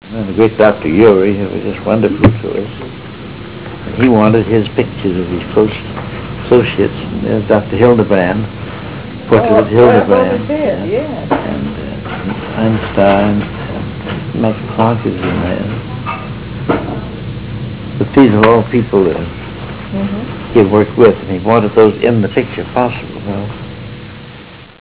199Kb Ulaw Soundfile Hear Ansel Adams discuss this photo: [199Kb Ulaw Soundfile]